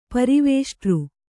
♪ pari vēṣṭř